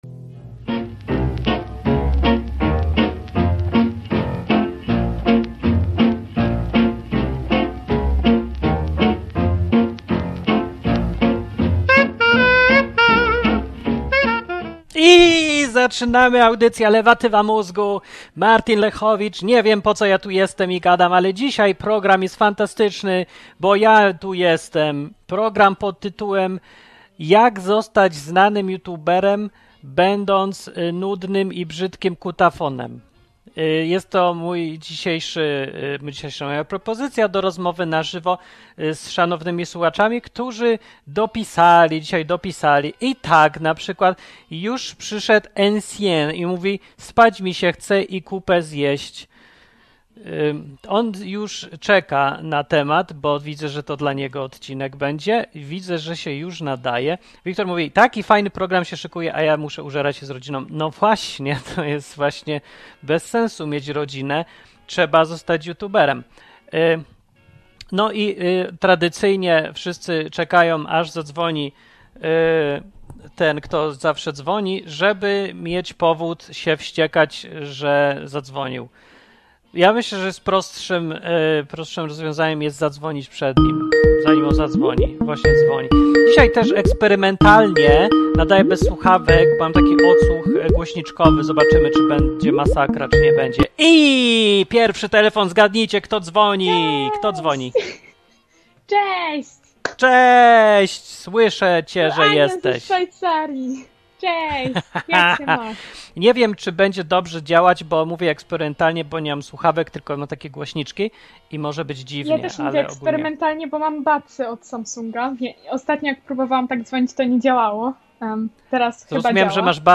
Drama internetowa: słuchacz dzwoni i przeprasza. Drugi słuchacz dzwoni i przeprasza pierwszego.